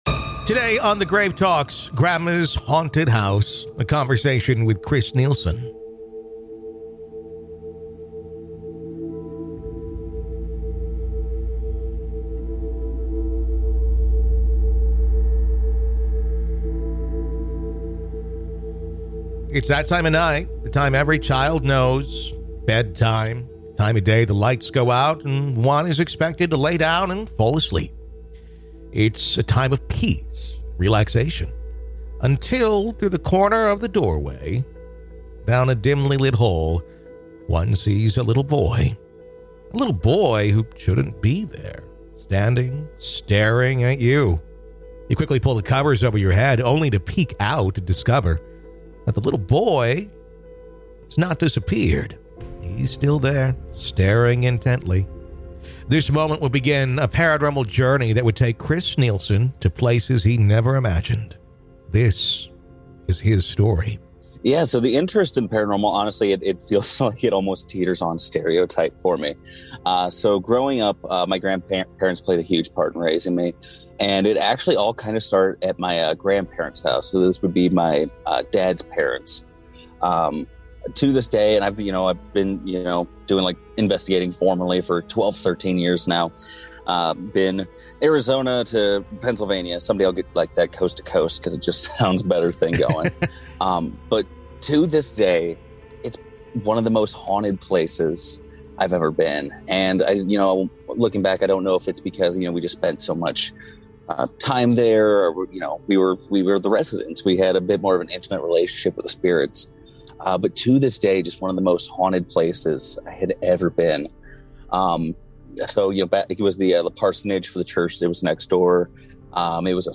If you enjoy our interviews and conversations about "The Dead," why not listen ad-free?